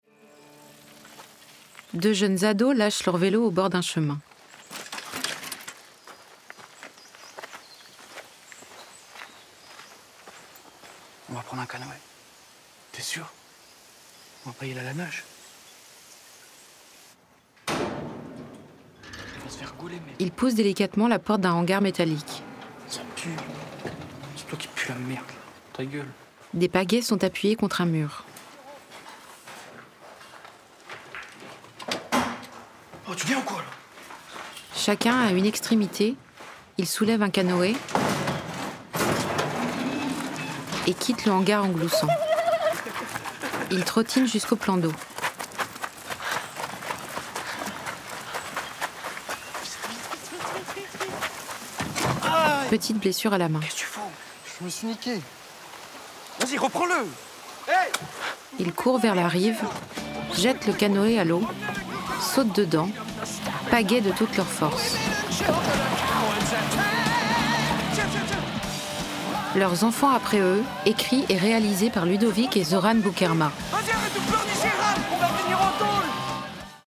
Ma voix est celle d’une femme entre trente et quarante ans qui a du coeur, de l’humanité, de la luminosité et une douceur qui convient par exemple très bien à la narration d’un drame historique.
Audio-description Fiction Cinéma : Leurs enfants après eux de Ludovic et Zoran Boukherma.